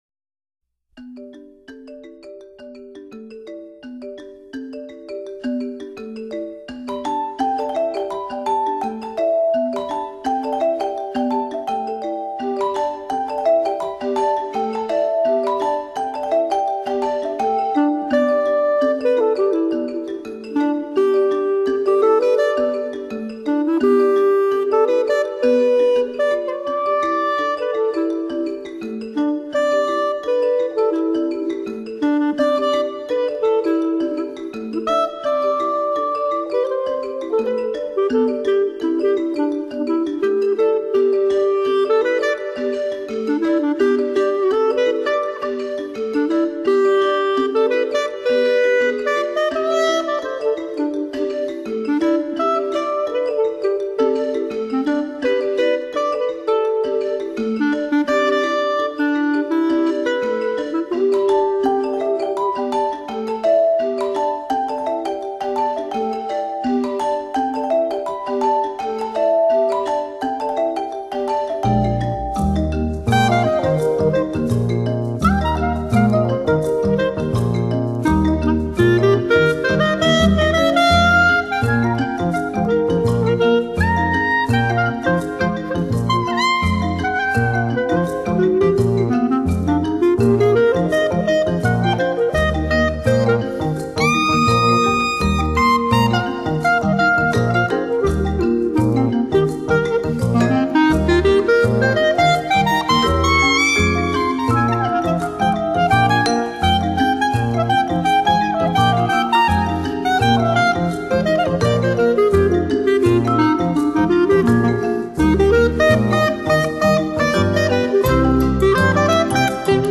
豎笛被稱為是最接近人聲的樂器，這裏所展現的豎笛音樂時而明亮耀眼，但又不至於像小號的逼人、刺眼；時而沉鬱，如泣如訴。